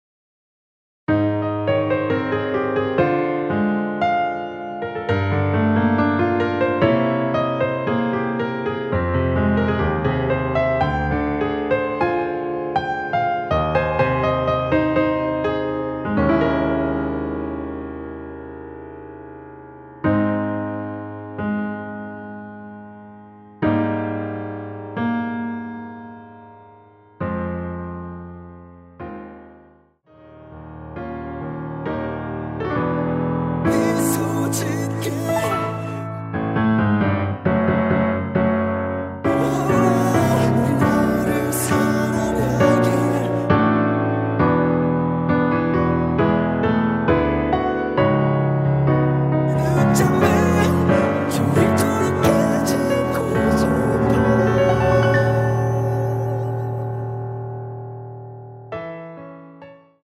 코러스 MR입니다.
원키 코러스 포함된 MR입니다.(미리듣기 참조)
Ab
앞부분30초, 뒷부분30초씩 편집해서 올려 드리고 있습니다.
중간에 음이 끈어지고 다시 나오는 이유는